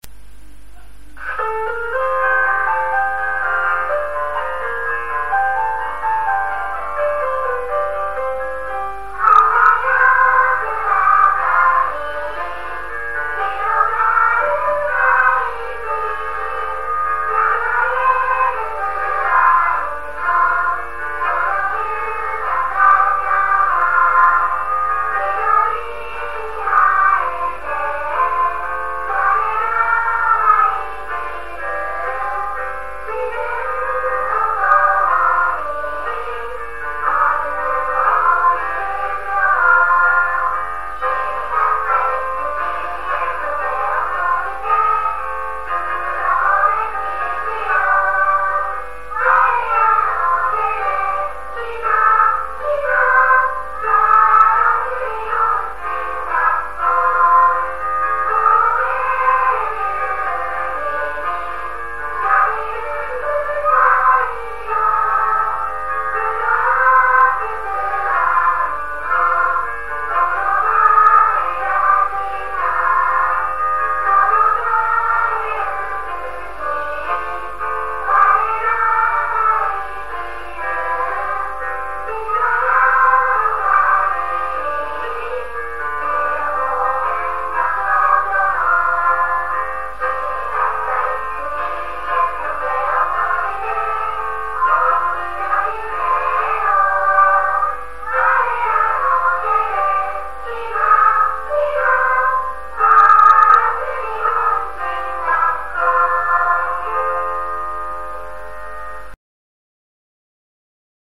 曲調も雄大な雰囲気がありとても気に入っています。
パース日本人学校_校歌（歌あり）.m4a